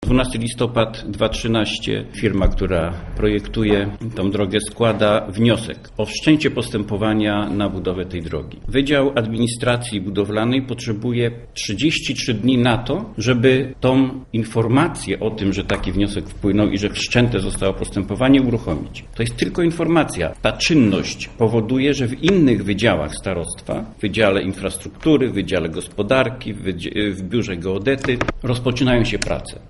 Zarzuty, jakie ustami władz miasta wyartykułował inwestor, dotyczą też wydziału administracji budowlanej starostwa, który opieszale wydaje decyzję zezwalającą na budowę łącznika wokół przyszłej galerii. Mówi Leszek Rybak, zastępca prezydenta.